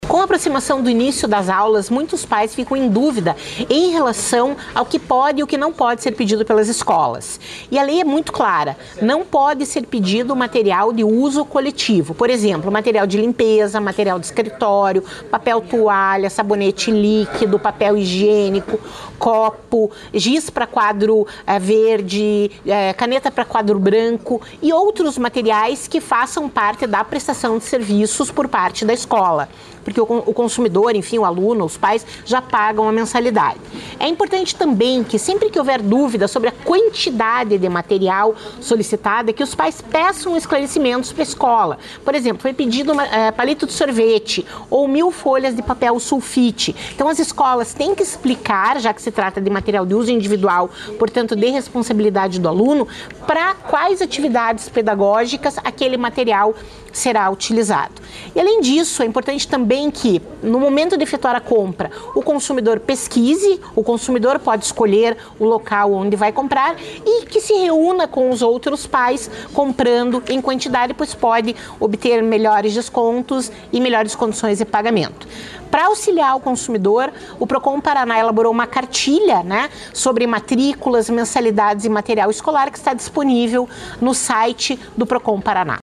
Sonora da diretora do Procon-PR, Cláudia Silvano, sobre a cartilha para orientar consumidores na volta às aulas